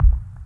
• Dark Techno Kick UK Style Blop.wav
Dark_Techno_Kick_UK_Style_Blop_WPr.wav